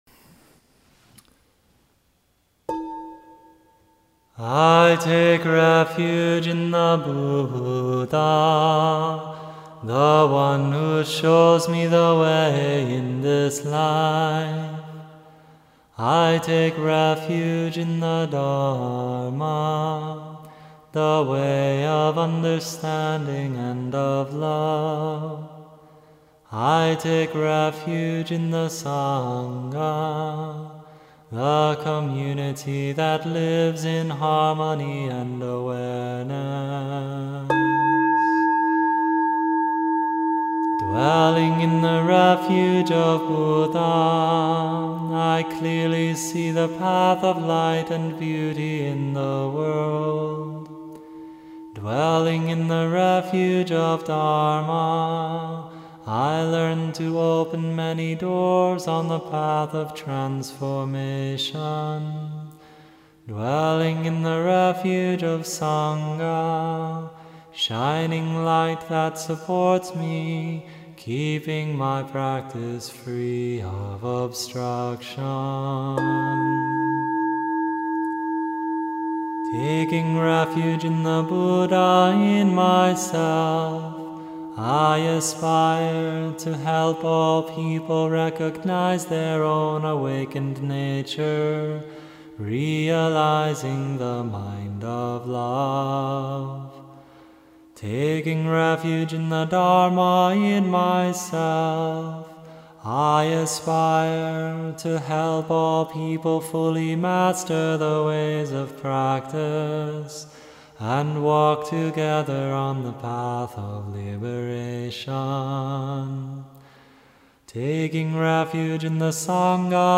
Chants / The Three Refuges